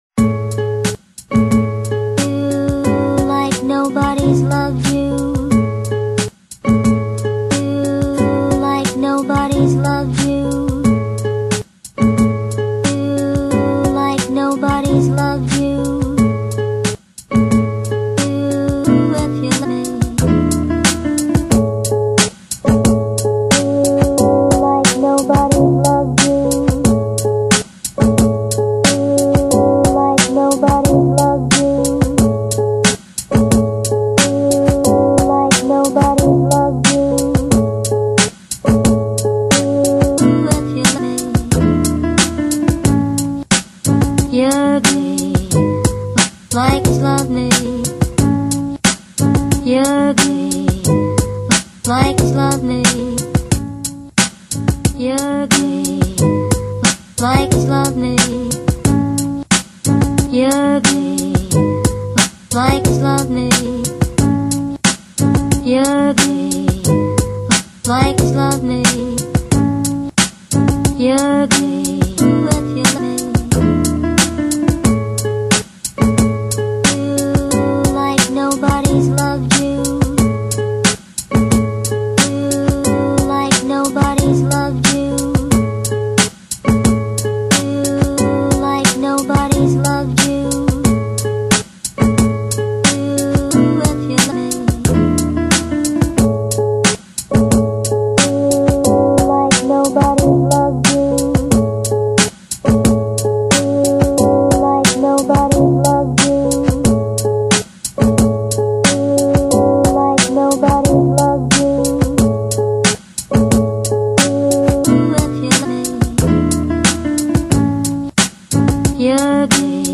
[5/12/2008][2/12/2008]『一首歌就能把你征服』舞曲系列之⒈【迷幻电音】
送上一首轻快的迷欢电子音乐给大家，希望大家喜欢 改歌曲在我整理时发现的，没有相应的资料~有知道的朋友请跟贴说一下